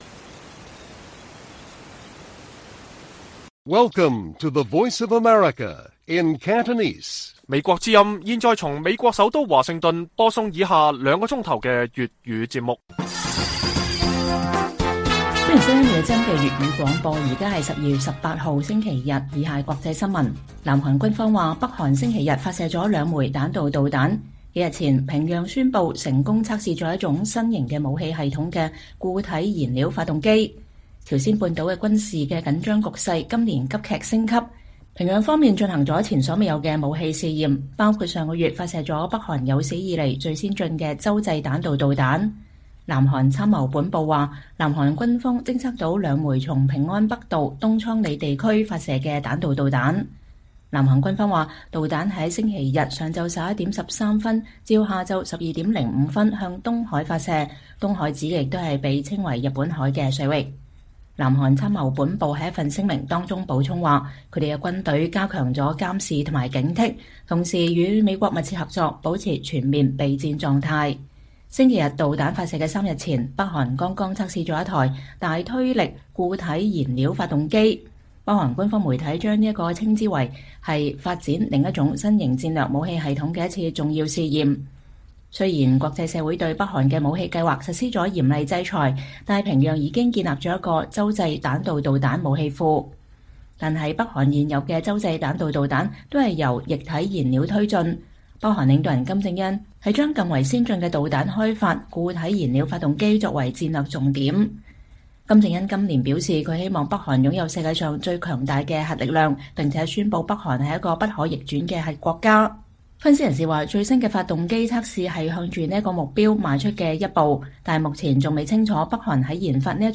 粵語新聞 晚上9-10點: 南韓軍方：北韓星期天發射了兩枚彈道導彈